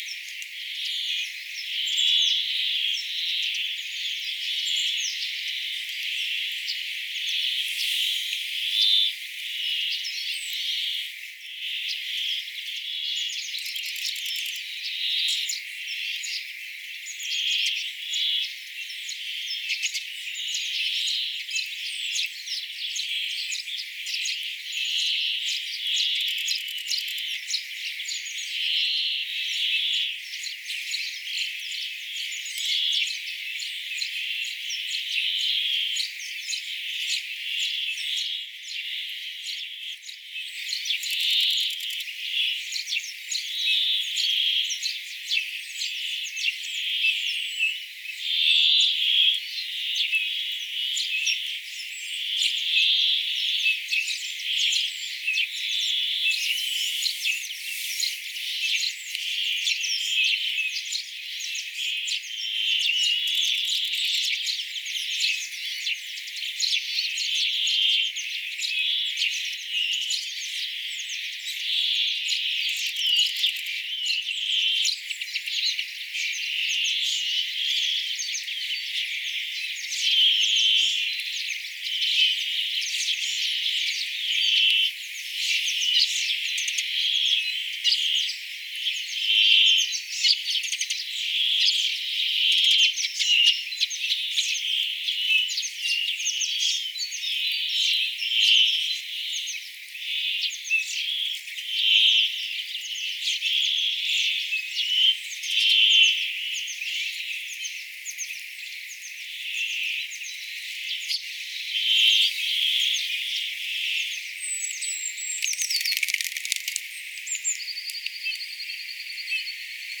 järripeippokonserttia oli edelleen
jarripeippokonserttia.mp3